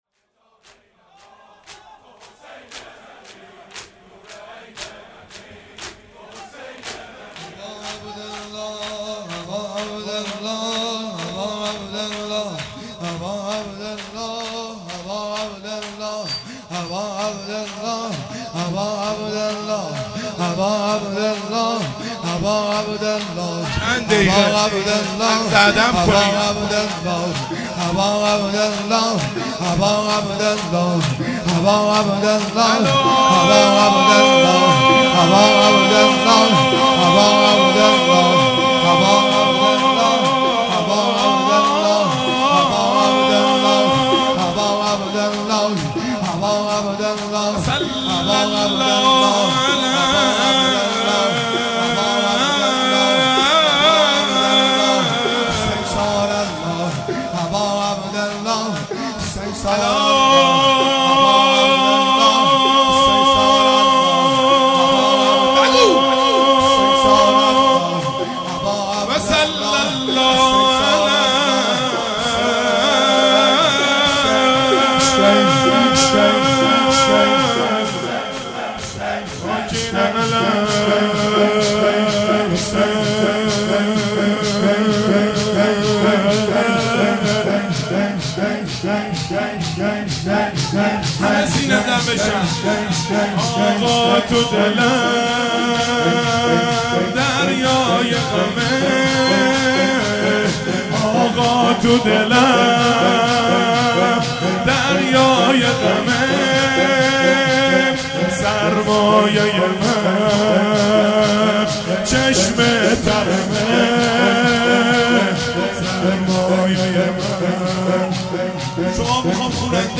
روضه
شور